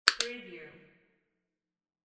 Handbag Lock Wav Sound Effect #5
Description: The sound of a handbag lock snapping open or close (two clicks)
Properties: 48.000 kHz 16-bit Stereo
Keywords: handbag, purse, bag, lock, locking, unlocking, snap, click, open, opening, close, closing, shut, shutting, latch, unlatch
handbag-lock-preview-5.mp3